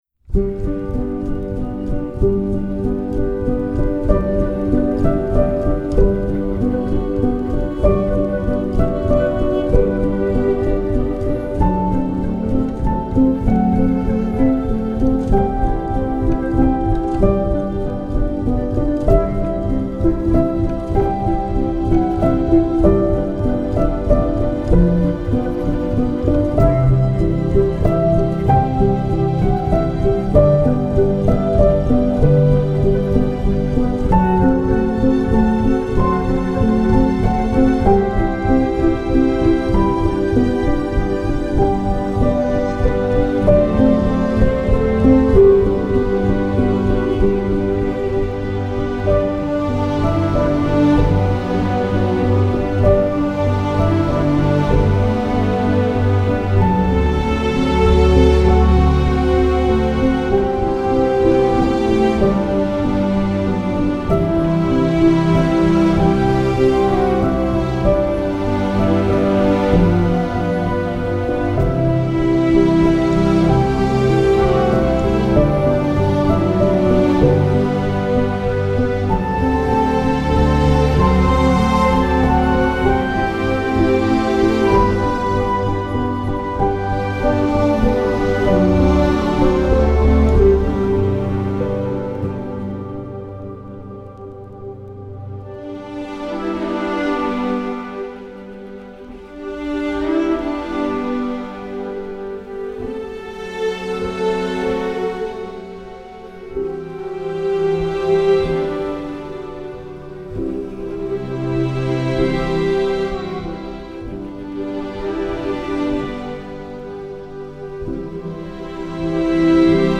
Cinematic arrangements of Christmas classics.